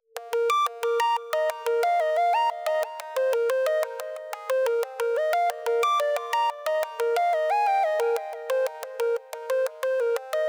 The signal is input to the high-pass filter having magnitude frequency response shown below.
Note that multiplication by in the frequency domain will suppress the output at low frequencies.
As expected, the low frequencies are suppressed.